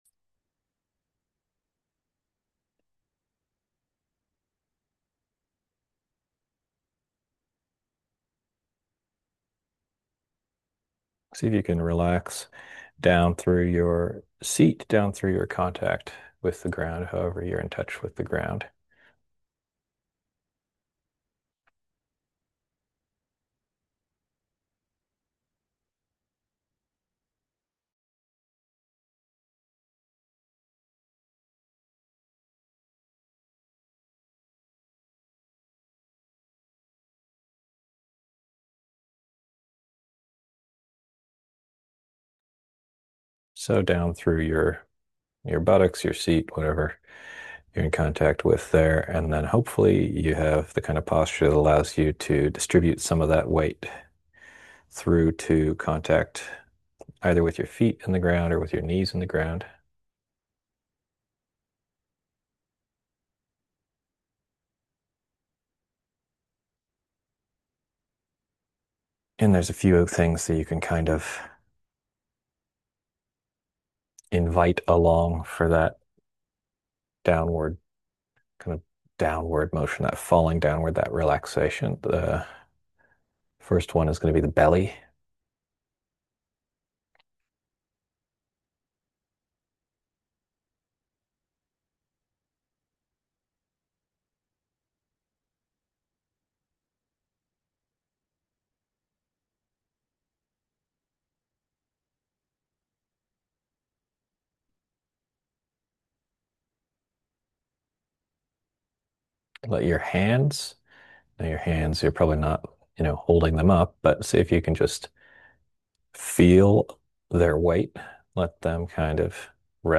Podcast (guided-meditations): Play in new window | Download